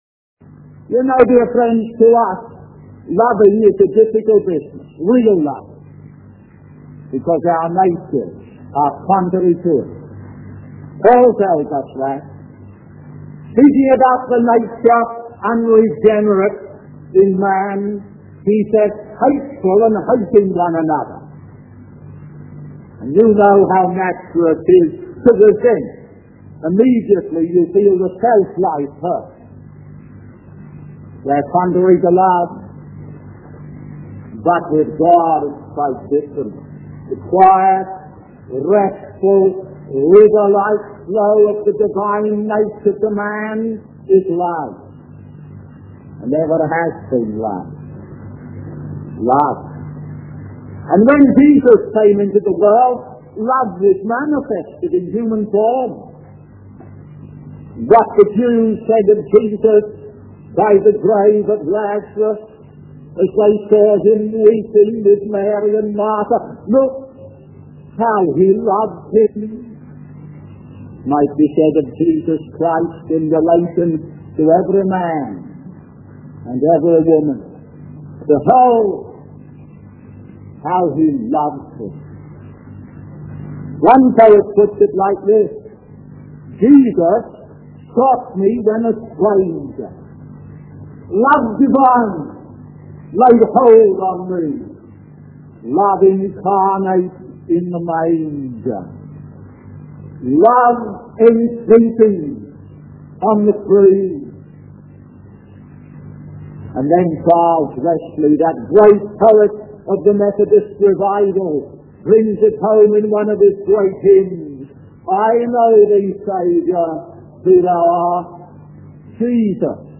In this sermon, the speaker emphasizes the importance of not just taking snapshots of our faith, but truly studying and immersing ourselves in the love of Christ.